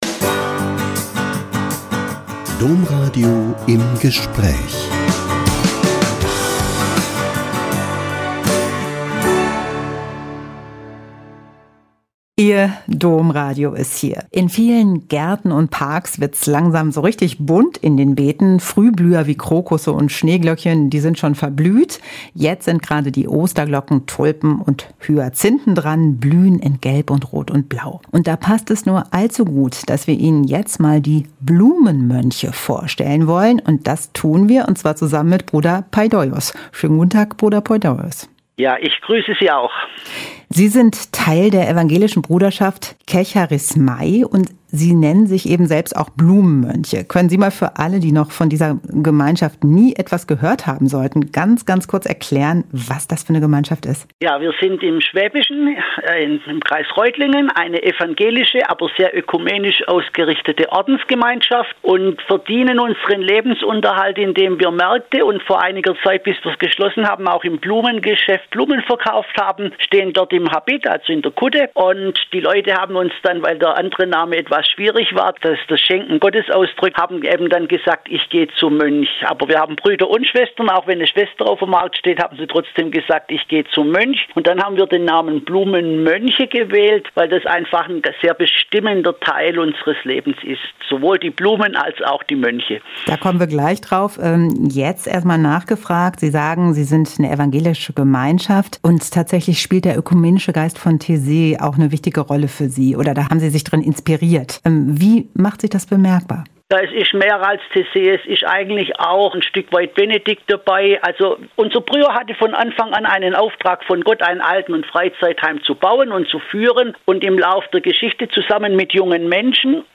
Blumenmönche fühlen sich der Schöpfung besonders verbunden - Ein Interview